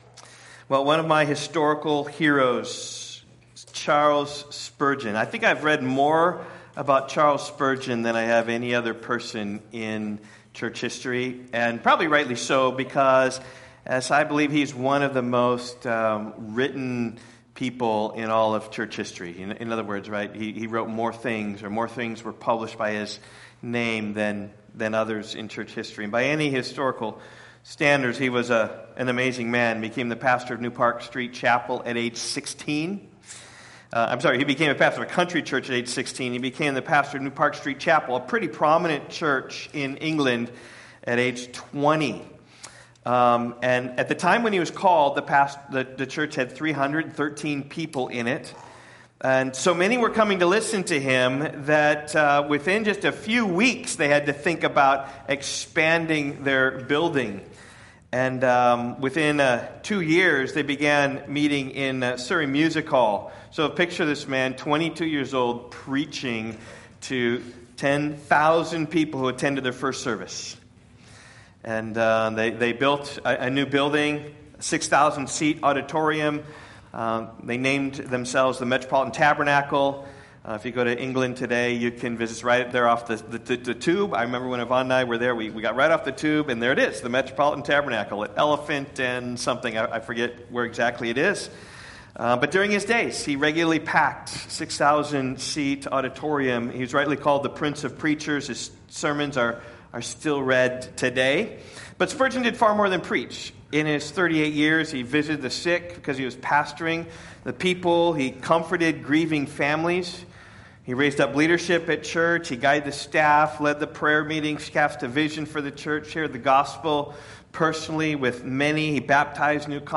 Sermon audio from Rock Valley Bible Church.
A Light Sermon John 8:12